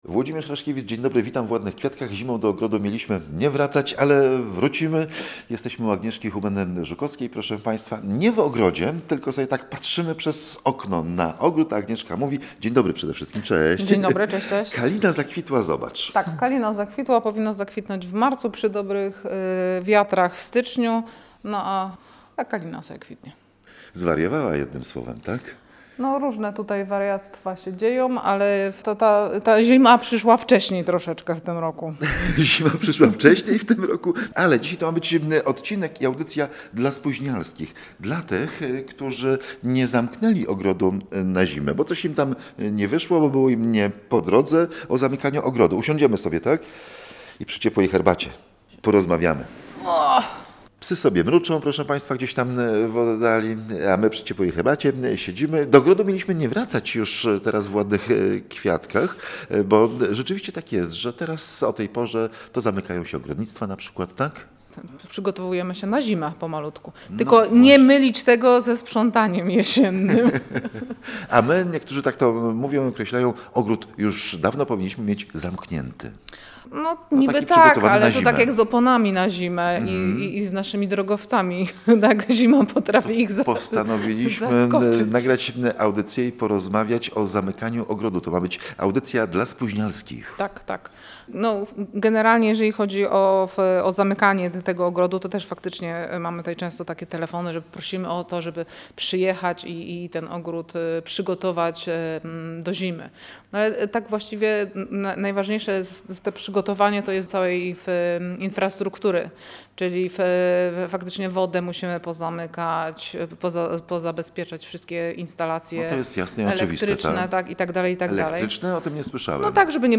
Która donica wytrzyma mrozy? Rozmowy o zimie w ogrodzie